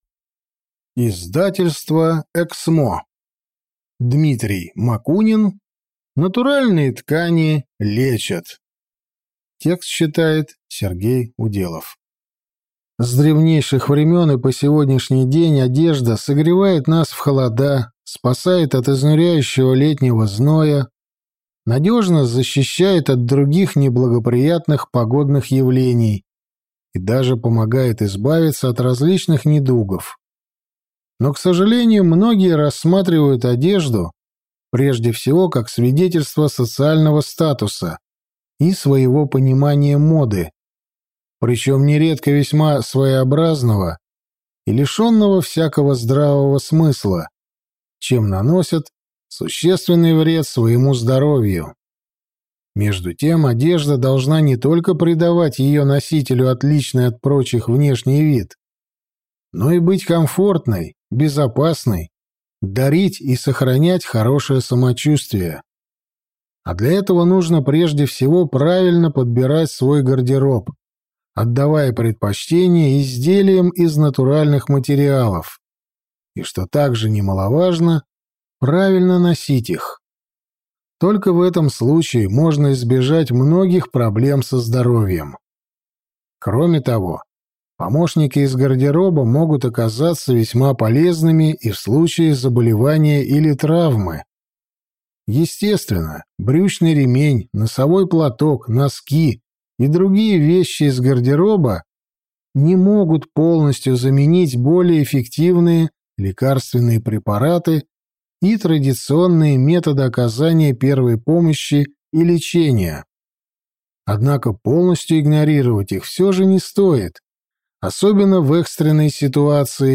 Аудиокнига Натуральные ткани лечат | Библиотека аудиокниг